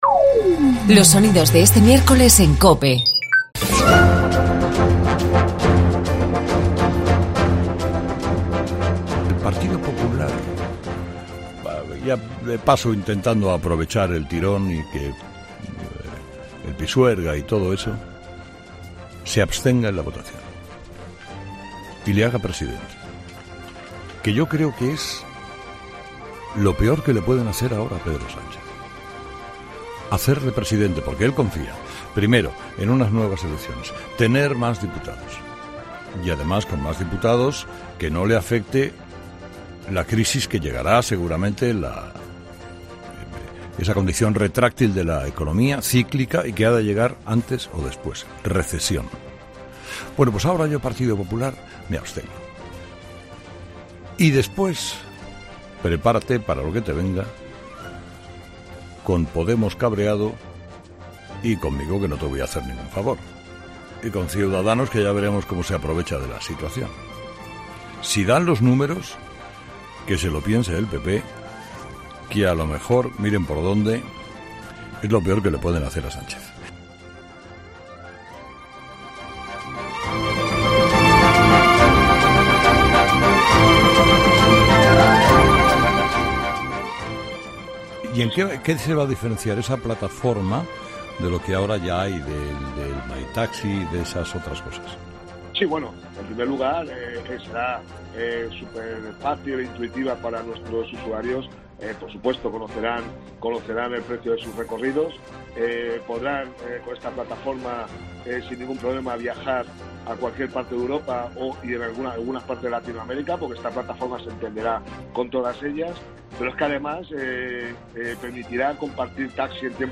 La entrevista a Rafa Nadal en 'El Partidazo de COPE' entre los mejores sonidos del día